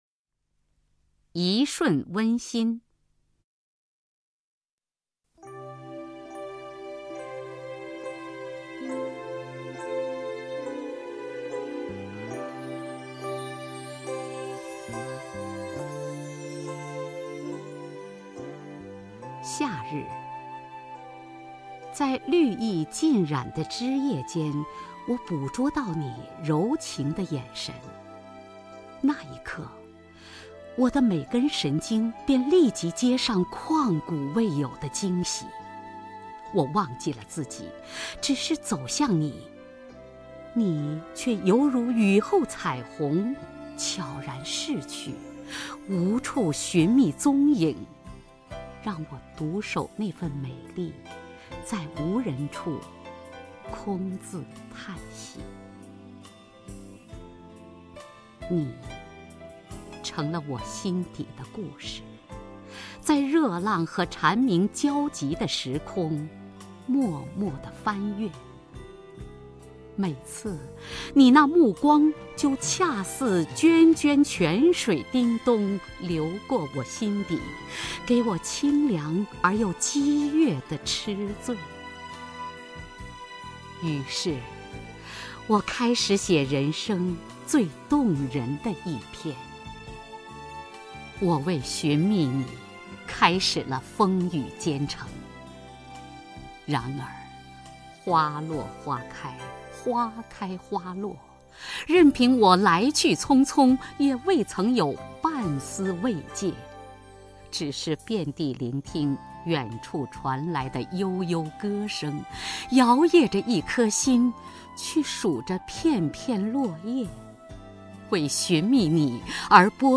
首页 视听 名家朗诵欣赏 雅坤
雅坤朗诵：《一瞬温馨》(思雨)